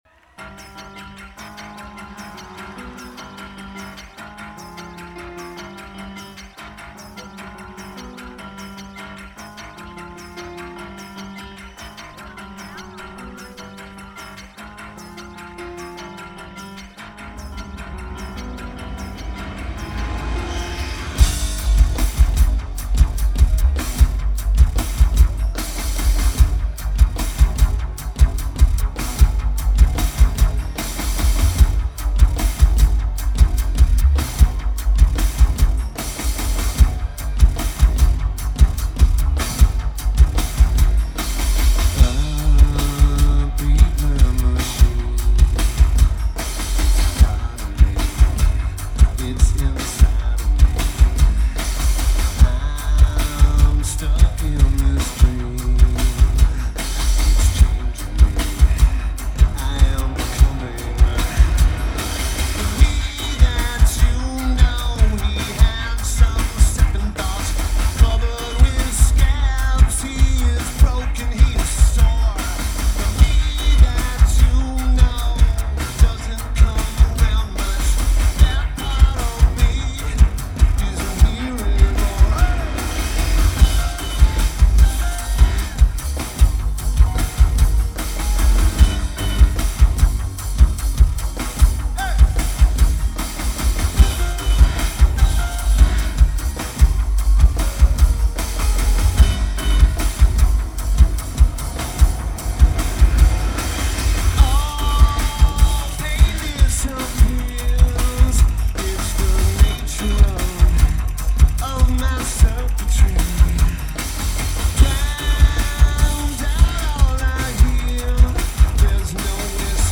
Red Hat Amphitheater
Raleigh, NC United States